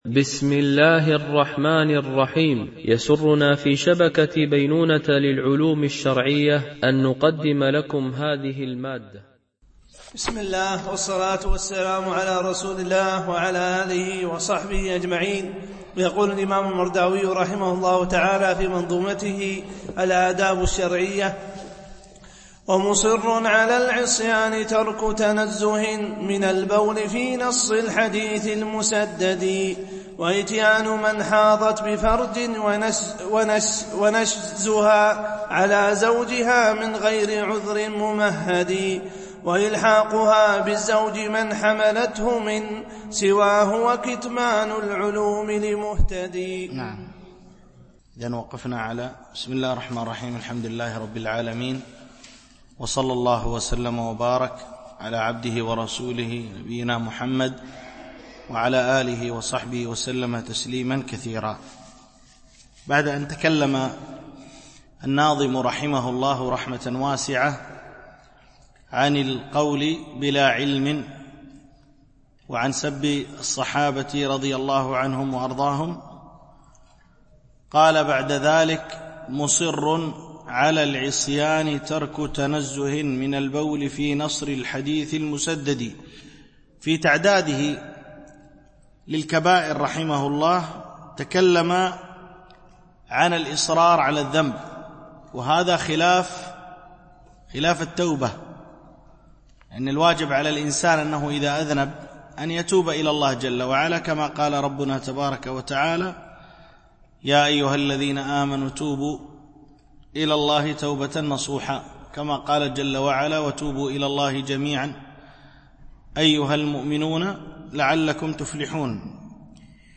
شرح منظومة الآداب الشرعية – الدرس 33 ( الأبيات 495 - 499 )